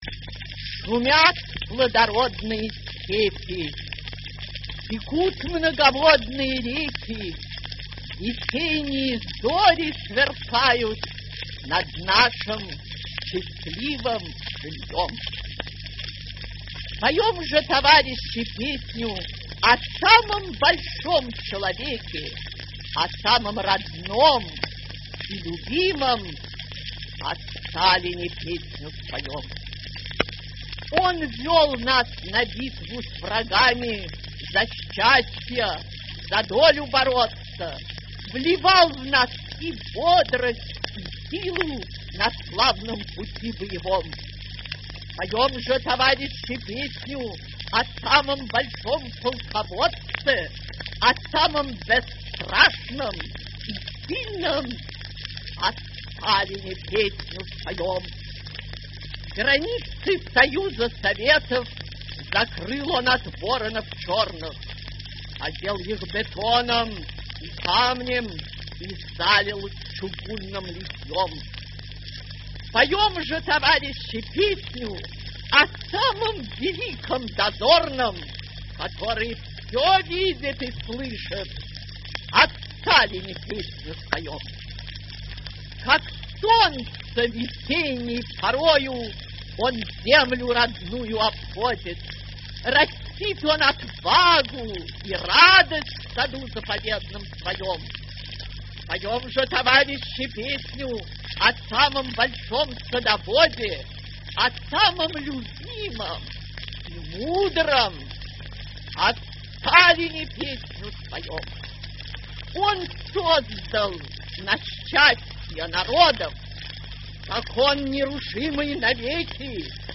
1. «Исаковский М. Читает А.Яблочкина (1937) – Песня о Сталине – стихотворение» /